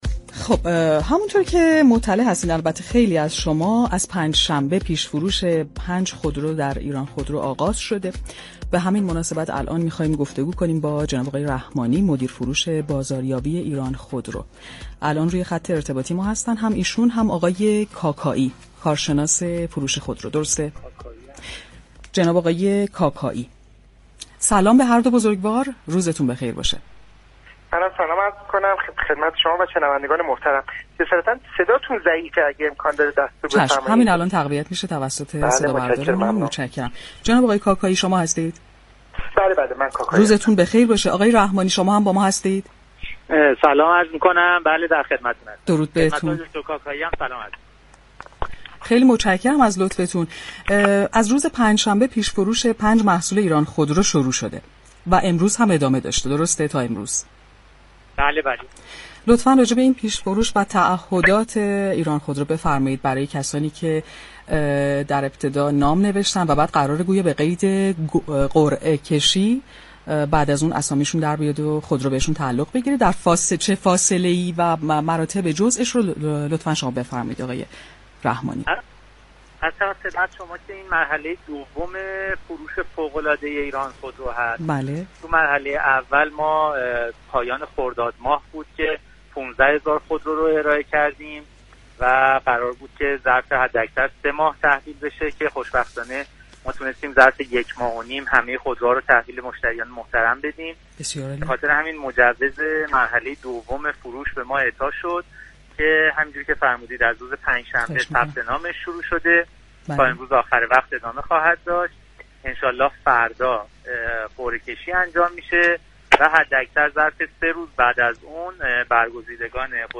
در گفت‌وگو با بازار رادیو تهران رادیو تهران تاثیر پیش فروش خودروبر قیمت بازار را بررسی كردند.